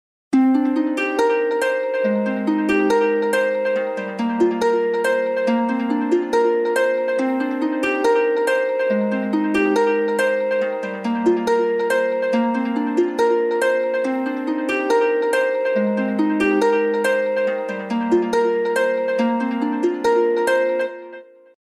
Звуки будильника iPhone